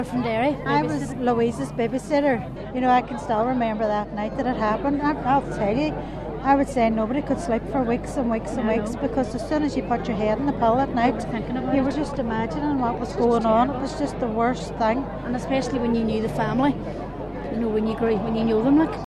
Event attendees say the entire community entered a state of shock at the time: